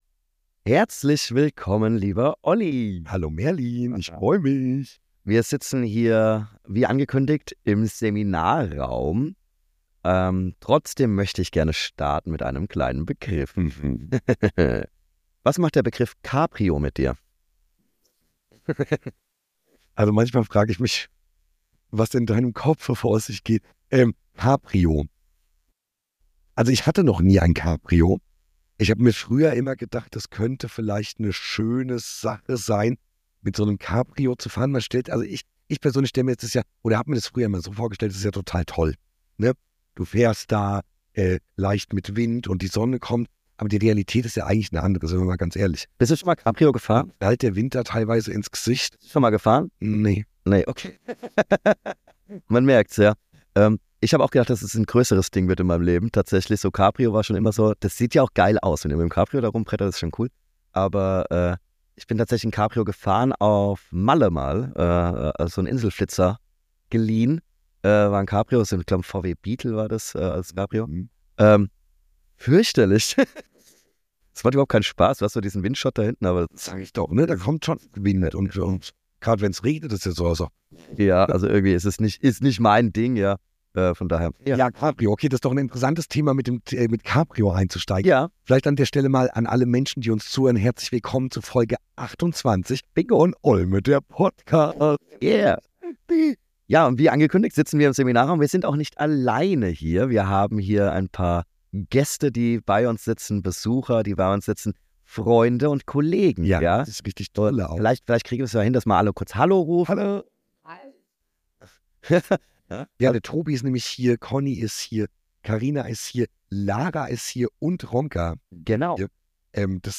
Beschreibung vor 1 Jahr Achtung Spezialfolge - Wir senden aus einem Seminarraum mit zahlreichen Gästen, Kollegen und Freunden. Gemeinsam reden wir in dieser Folge über Politik, Film, Ägypten, Verschwörungstheorien und selbst Roy Black findet unerwartet seinen Platz. Neben viel Gelächter und lustigen Anekdoten wird es auch mal ernst.